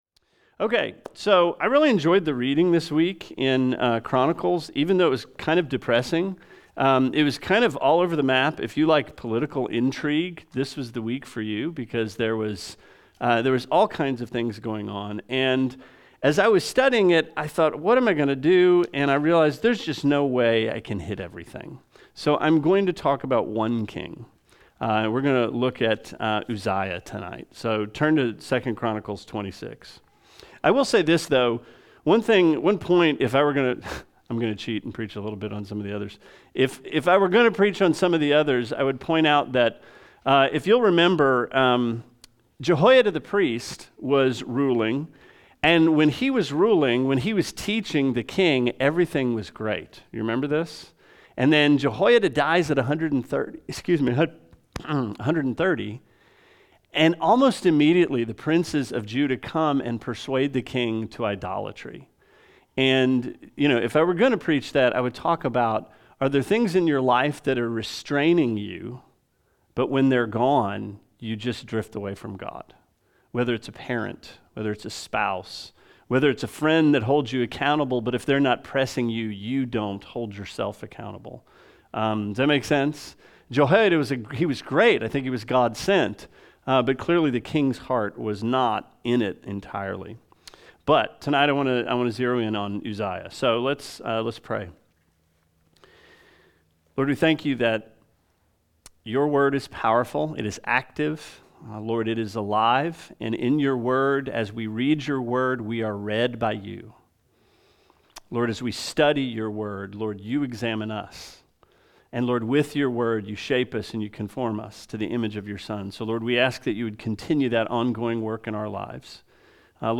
Sermon 11/17: The Way Up is the Way Down